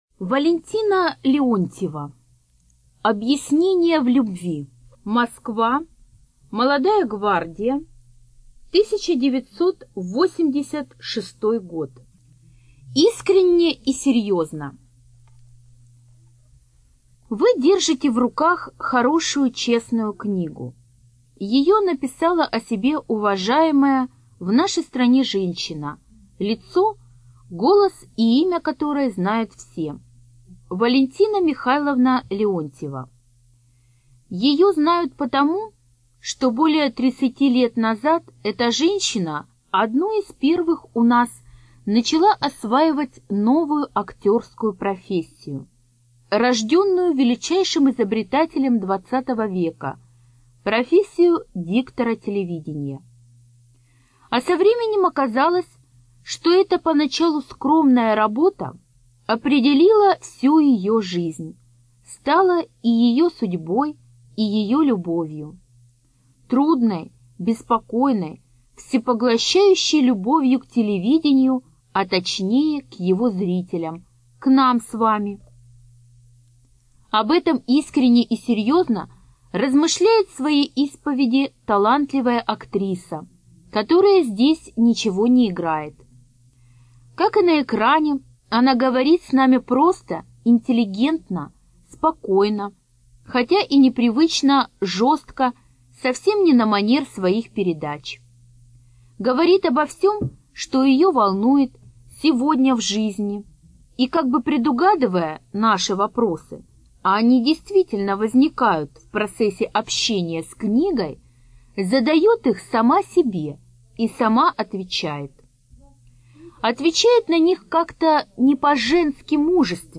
Студия звукозаписиРостовская областная библиотека для слепых